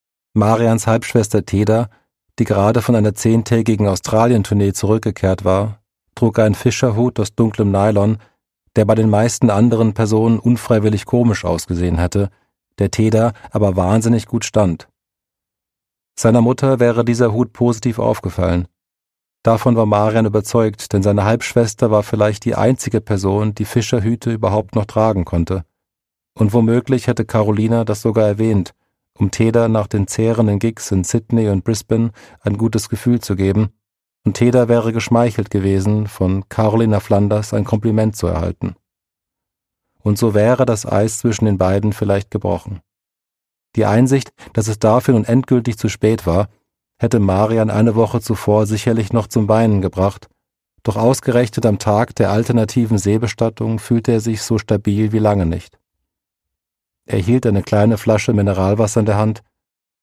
Produkttyp: Hörbuch-Download
Gelesen von: Leif Randt